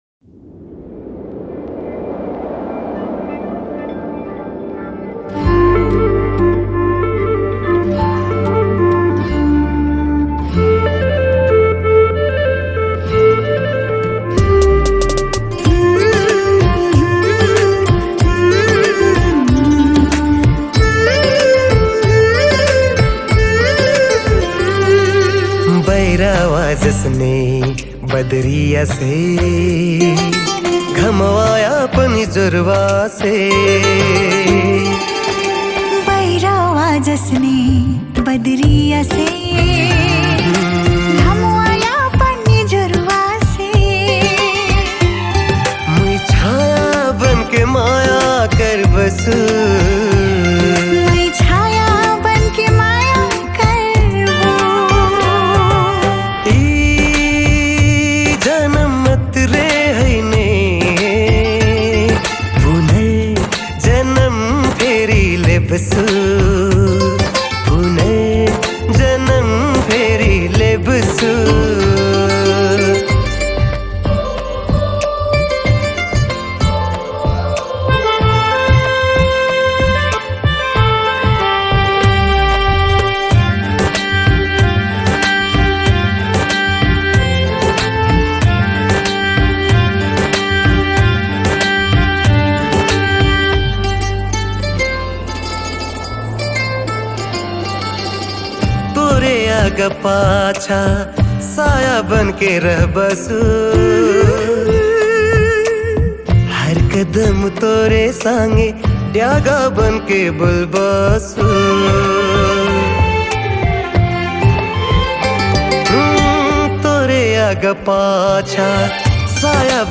New Tharu Mp3 Song